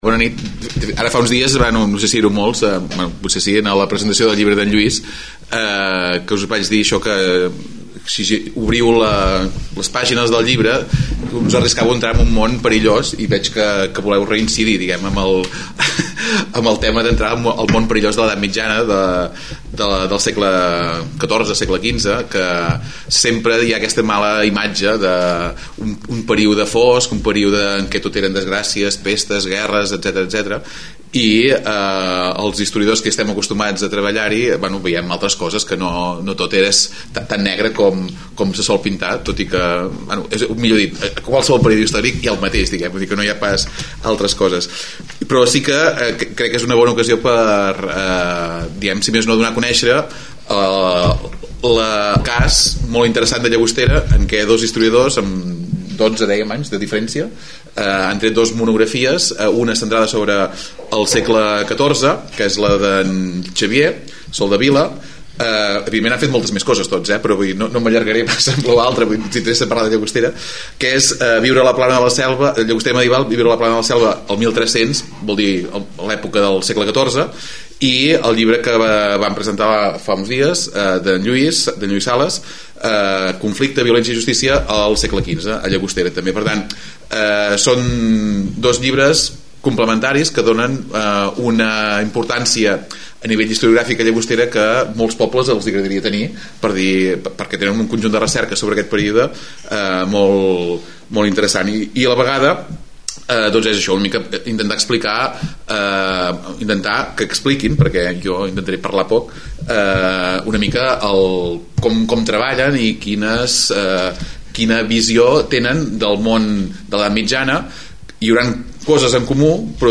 CONVERSA ENTRE DOS SEGLES
Tres medievalistes parlant de Llaogstera !! Gravació en directe del col.locqui fet el 10 de maig amb el suport de Llagostera Radio .